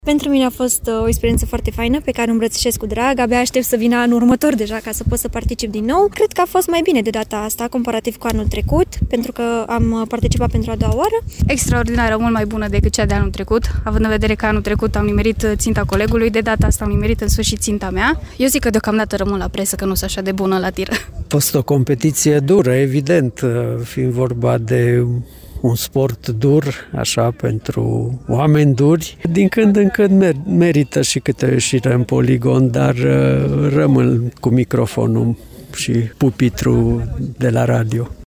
Aceştia şi-au dat întâlnire în Poligonul din Sângeorgiu de Mureş pentru a participa la Cupa Presei la Tir, eveniment tradiţional organizat cu prilejul Zilei Jandarmeriei.
Experienţa a fost una plăcută, dar la final, jurnaliştii au spus că aleg să rămână totuşi la ceea ce fac ei mai bine: